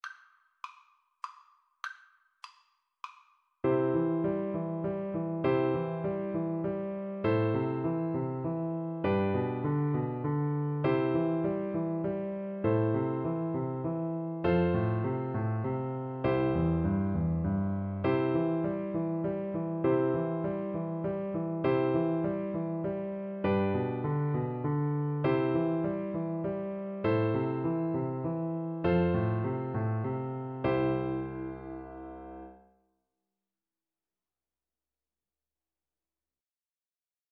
Play (or use space bar on your keyboard) Pause Music Playalong - Piano Accompaniment Playalong Band Accompaniment not yet available reset tempo print settings full screen
"Arirang" is a Korean folk song, often considered as the unofficial national anthem of Korea.
C major (Sounding Pitch) (View more C major Music for Recorder )
3/4 (View more 3/4 Music)